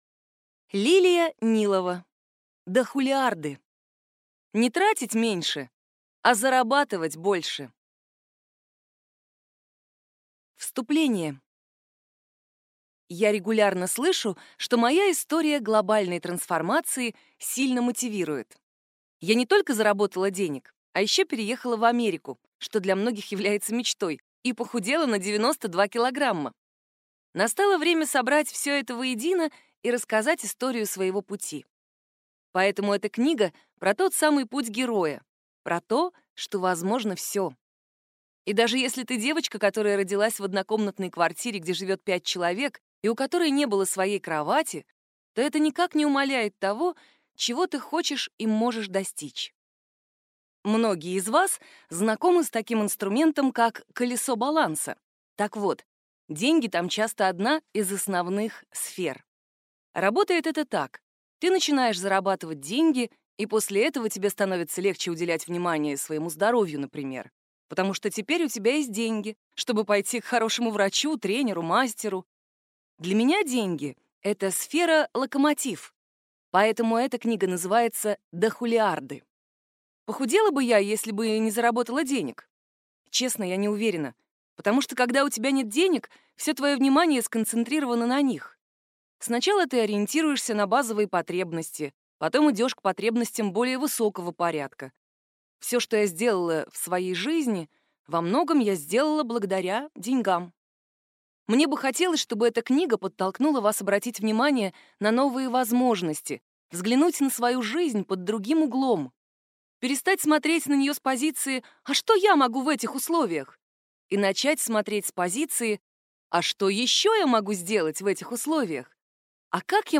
Аудиокнига Дохулиарды. Не тратить меньше, а зарабатывать больше | Библиотека аудиокниг